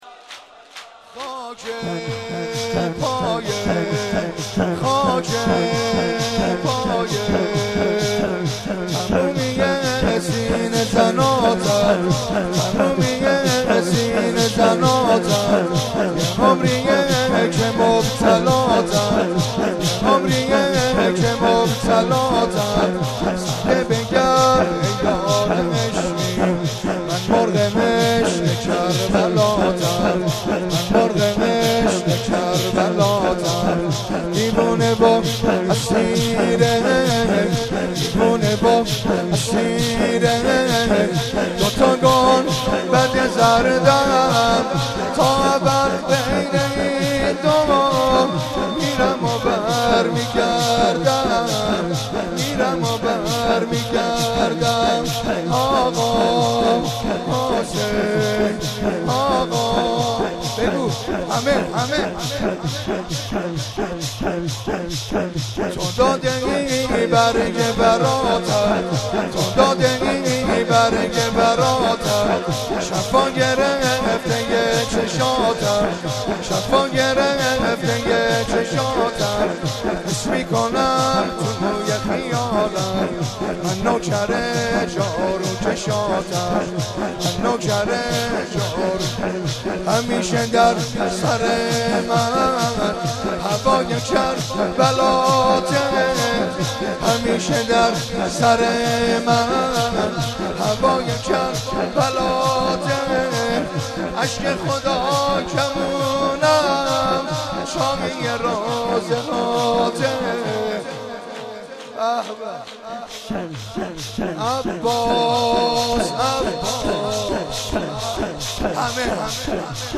04.sineh zani.mp3